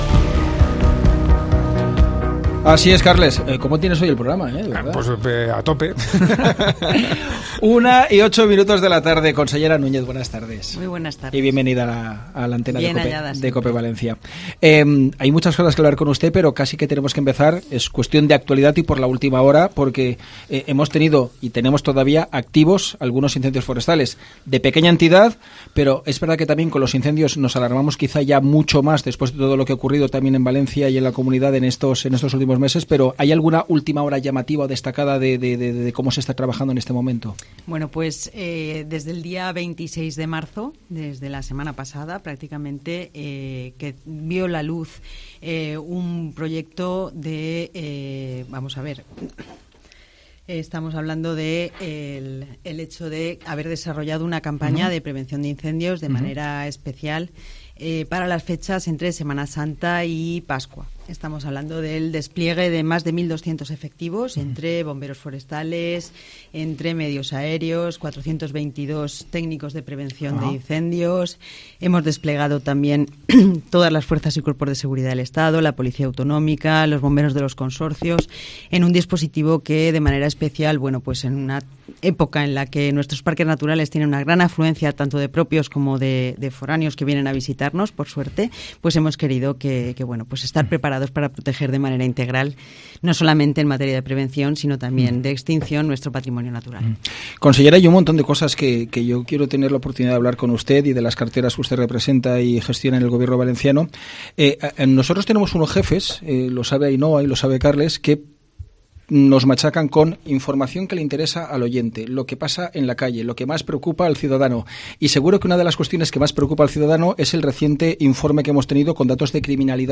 La Consellera de Justicia, Elisa Núñez, visita los estudios de COPE Valencia para hablar sobre todos temas de actualidad relacionados con su cartera
Entrevista a Elisa Núñez en COPE Valencia